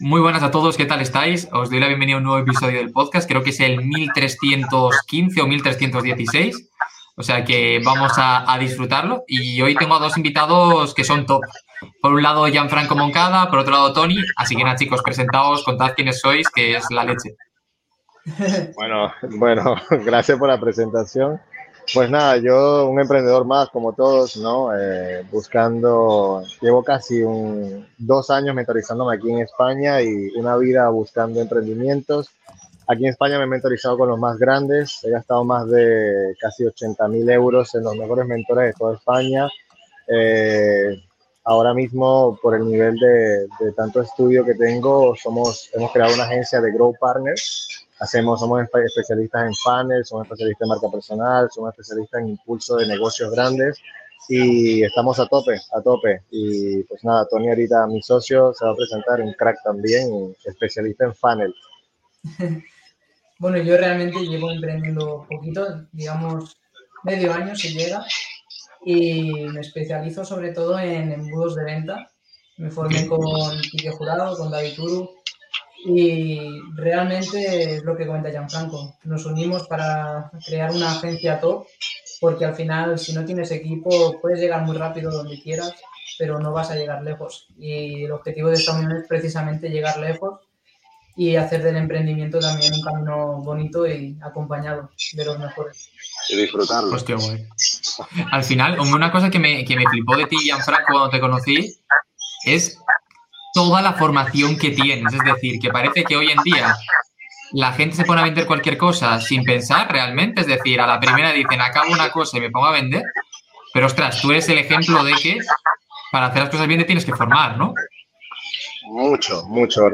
Entrevista | La agencia más potente de 2024